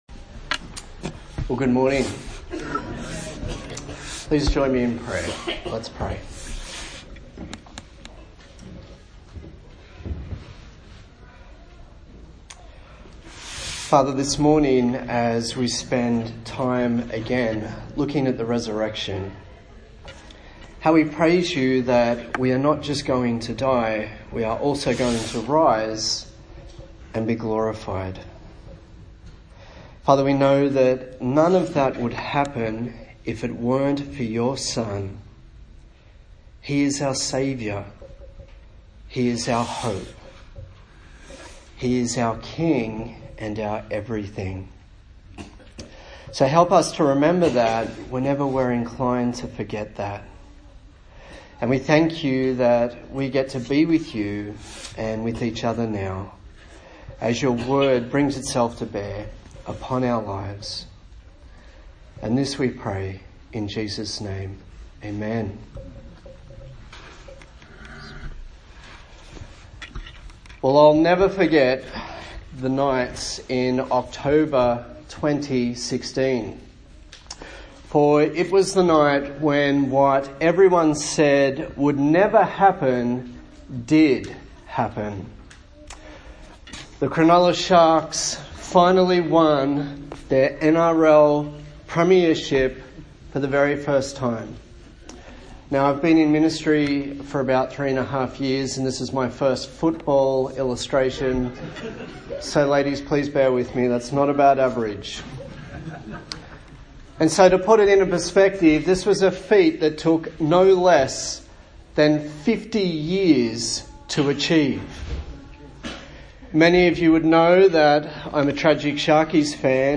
A sermon in the series on the resurrection in the book of 1 Corinthians
Service Type: Sunday Morning